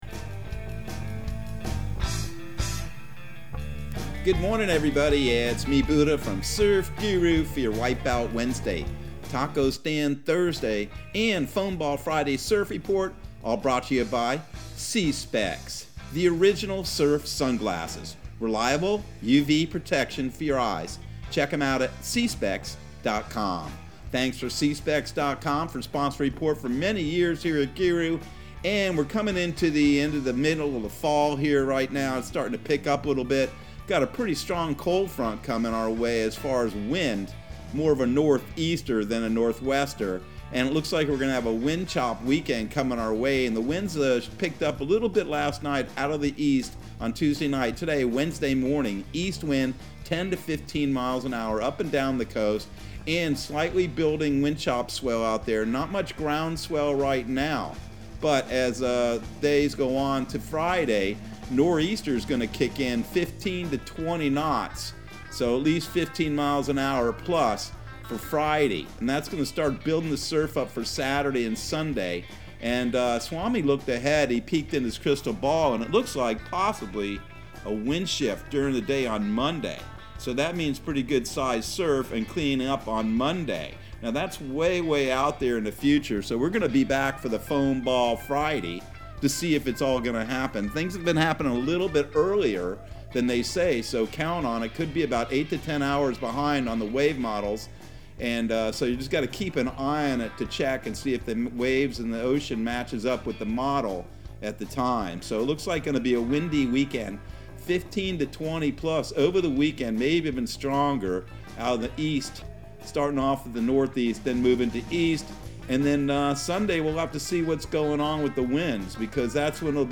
Surf Guru Surf Report and Forecast 11/17/2021 Audio surf report and surf forecast on November 17 for Central Florida and the Southeast.